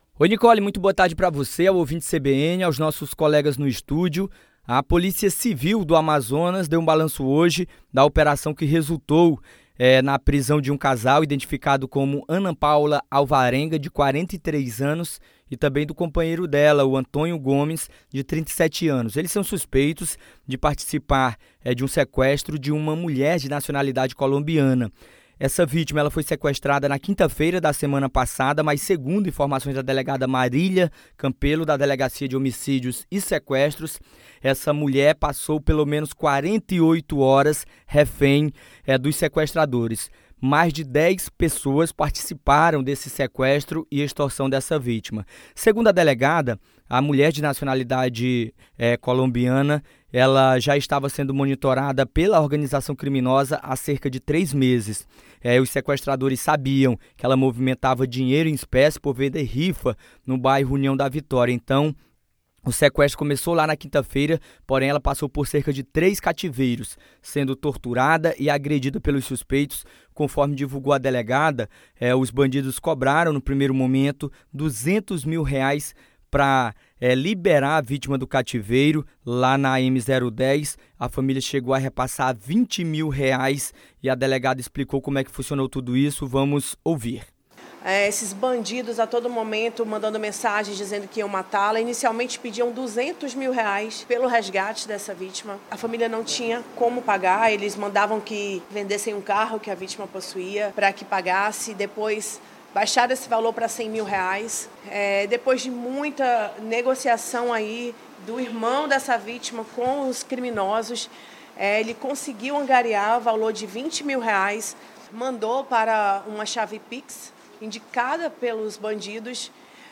COLETIVA OPERAÇÃO ANTISSEQUESTRO